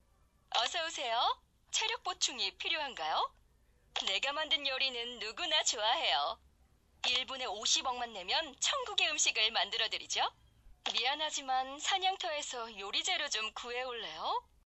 성우샘플